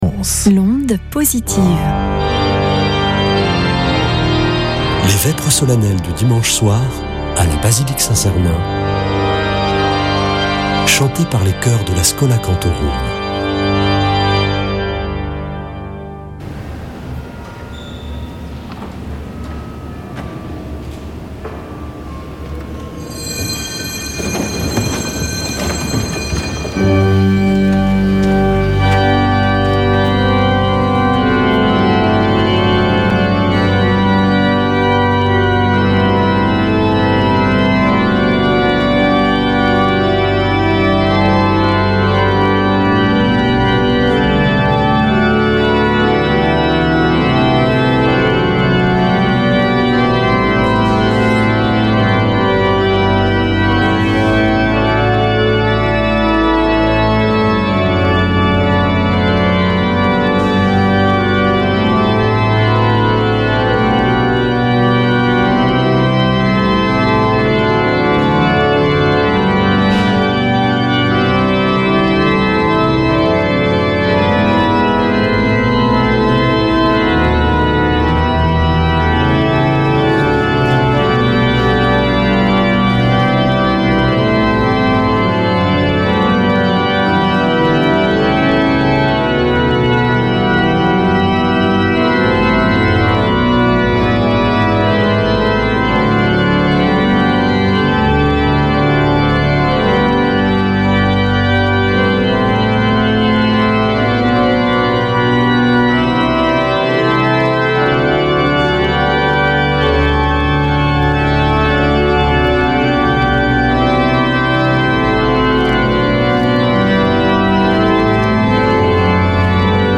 Chanteurs